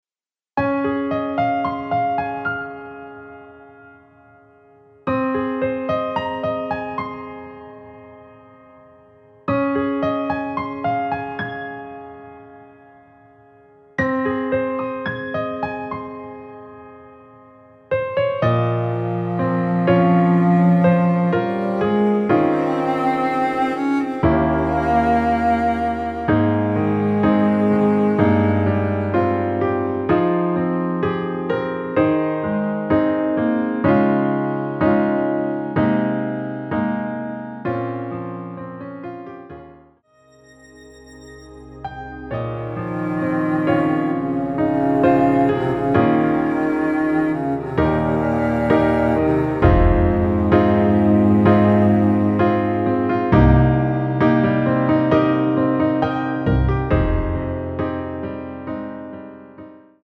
엔딩이 페이드 아웃이라 라이브 하시기 좋게 엔딩을 만들어 놓았습니다.(여자키 미리듣기 참조)
Ab
앞부분30초, 뒷부분30초씩 편집해서 올려 드리고 있습니다.